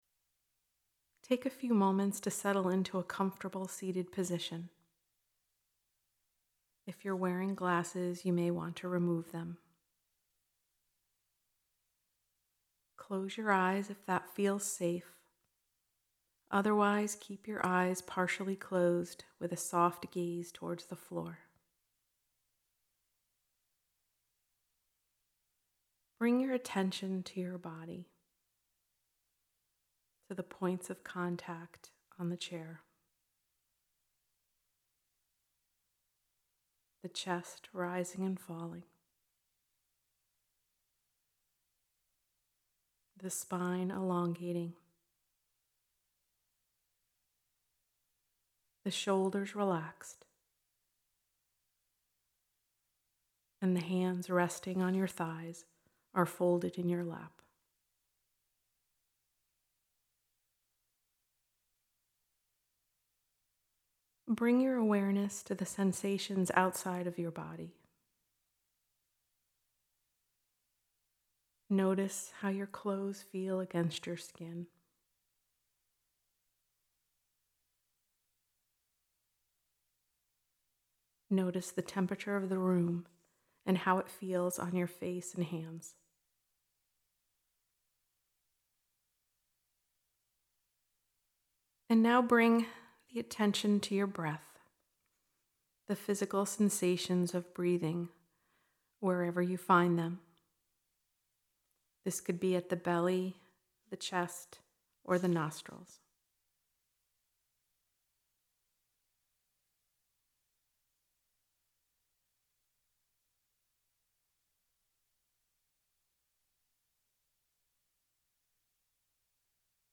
7-minute mindfulness meditation practice I recorded just for you.